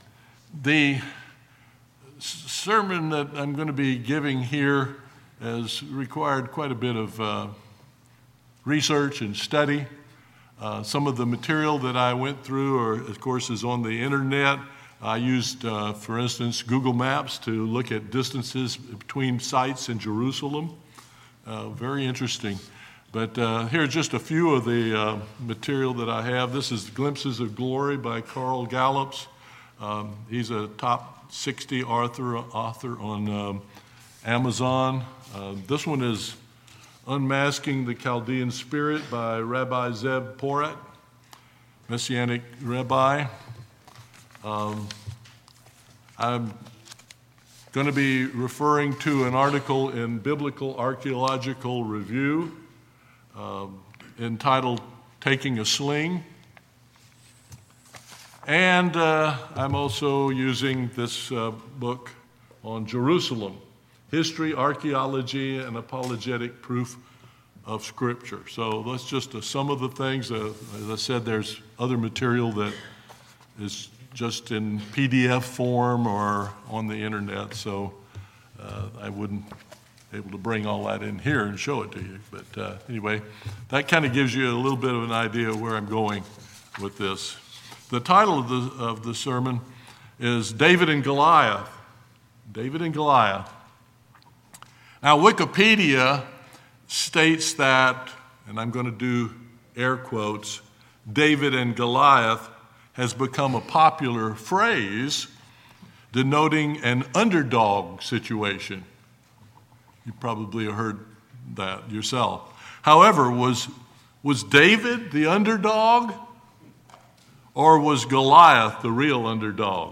Was David the "underdog", or was Goliath really the "underdog"?David's spiritual perspective told him the battle was the Lord's. This concise sermon covers inspiring scriptural details and archaeological discovery showing how the story of David and Goliath ties into Bible prophecy and Jesus Christ's return to the Mount of Olives.